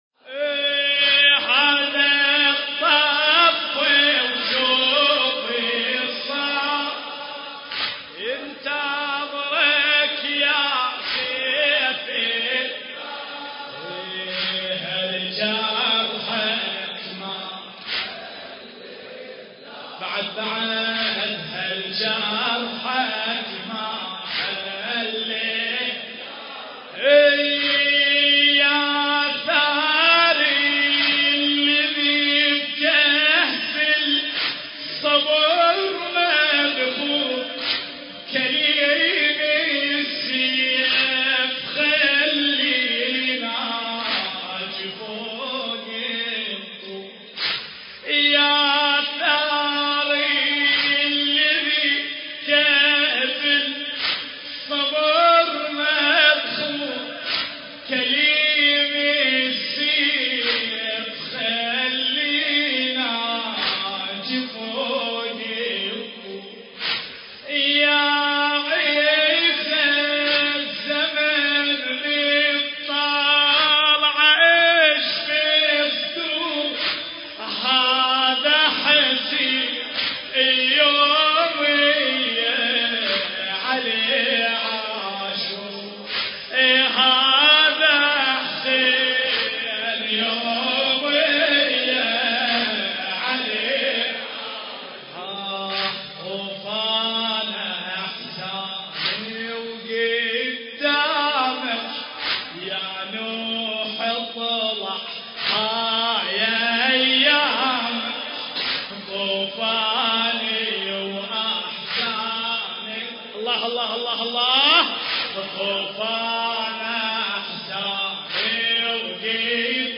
المكان: موكب النجف الأشرف – جامع الجواهري